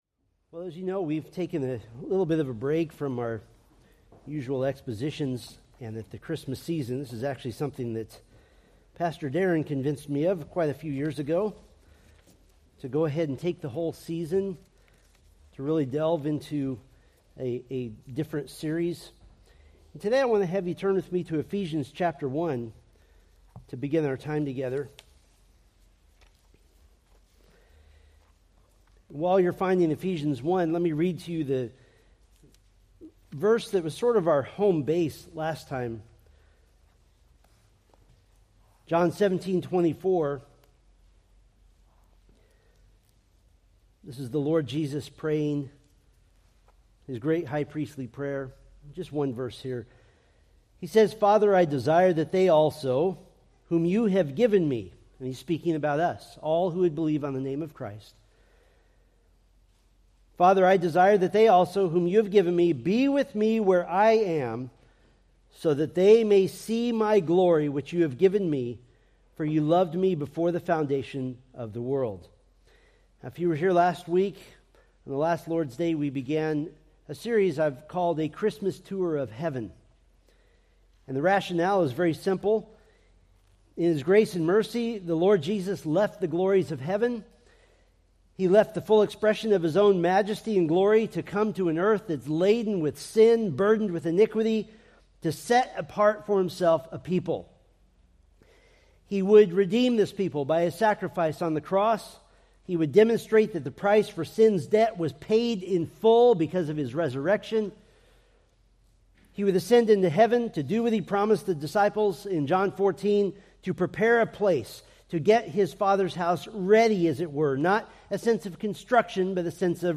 Preached December 15, 2024 from Selected Scriptures